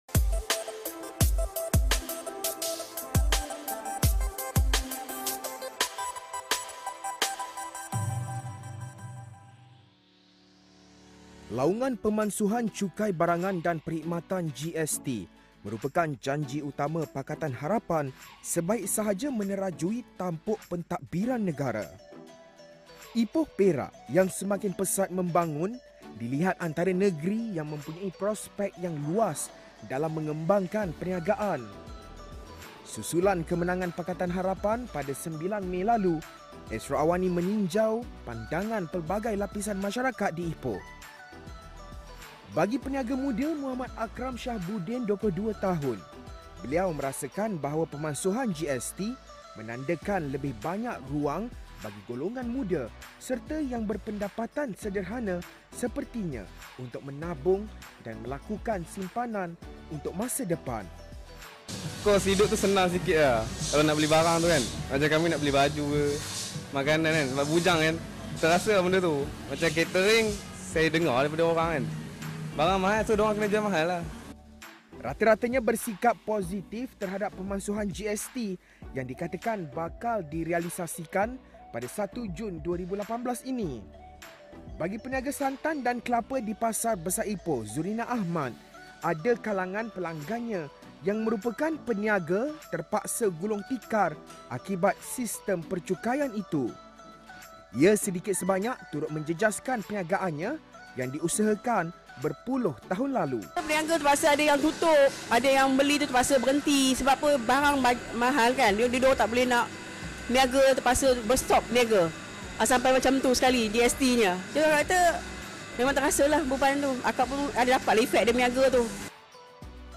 meninjau pandangan pelbagai lapisan masyarakat di Ipoh